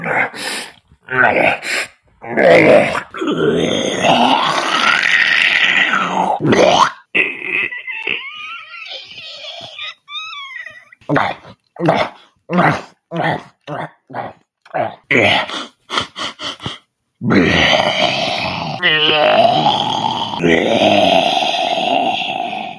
Zombie Noises
A wide range of noises. From eating, to attacking, sniffing out prey, and other noises.
zombie_noises_0.mp3